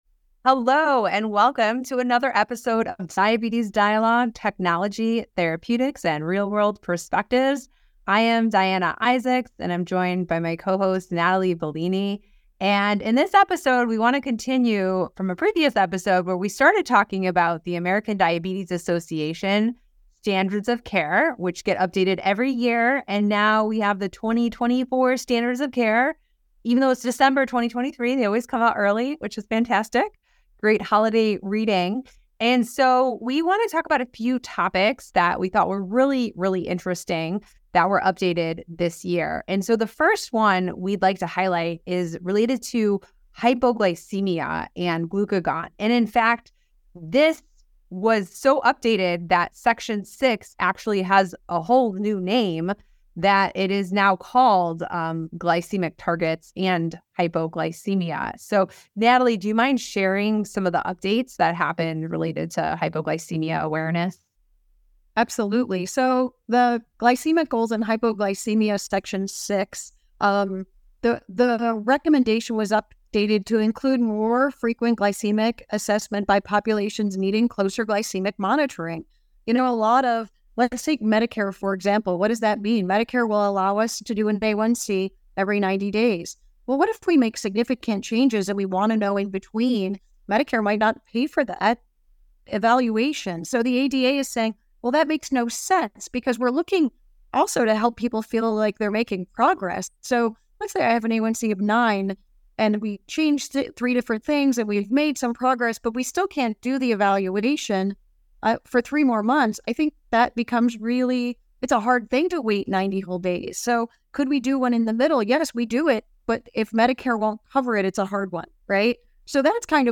Hosts discuss what they consider the most significant or relevant updates in the ADA's Standards of Care—2024, with a focus on new recommendations for glycemic management, bone health, immunizations, and weight management.